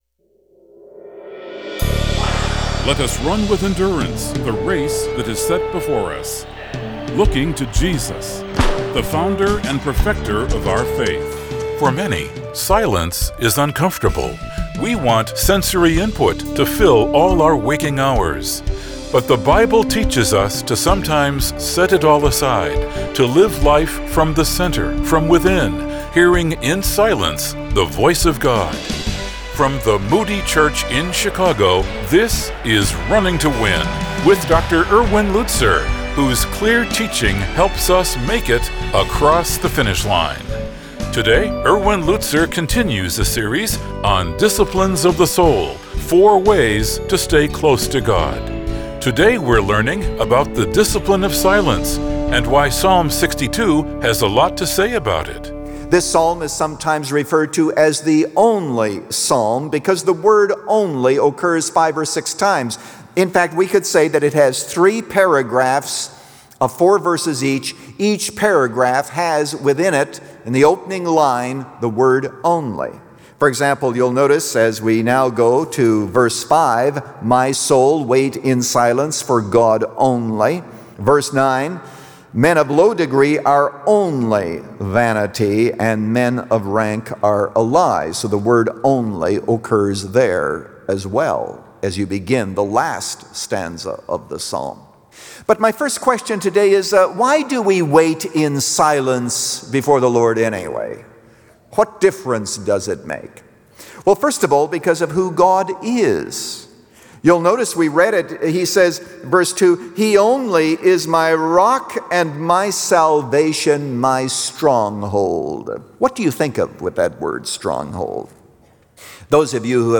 Since 1998, this 15-minute program has provided a Godward focus.